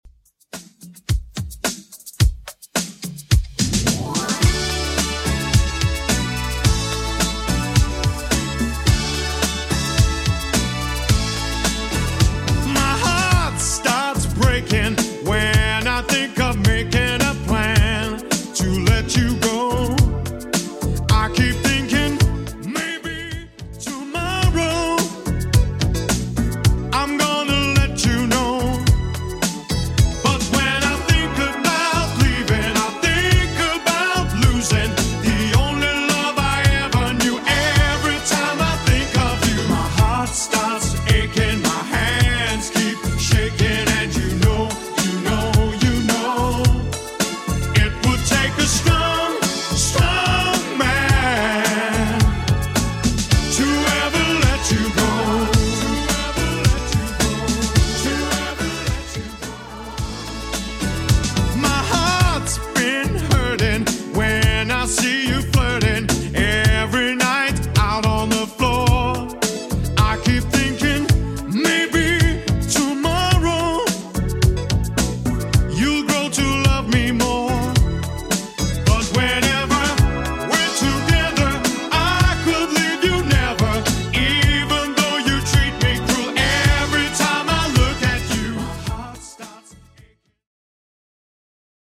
BPM: 108 Time